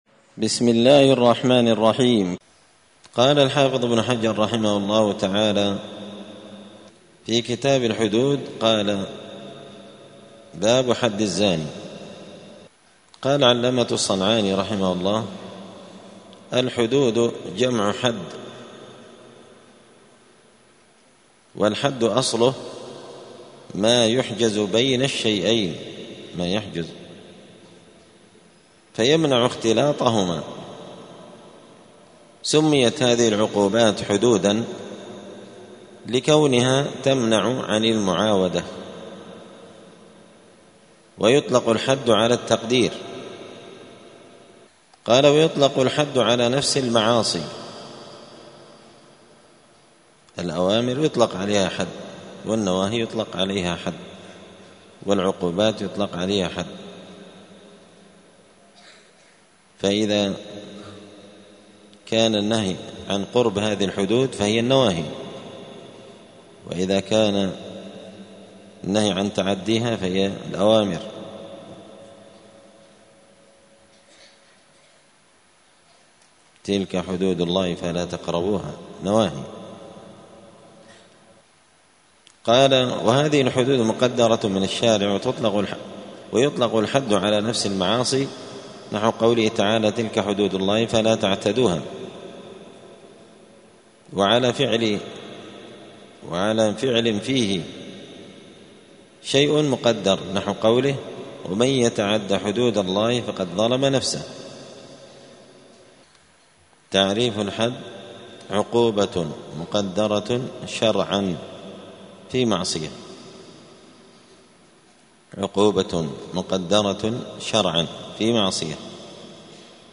*الدرس الأول (1) {باب حد الزنا}*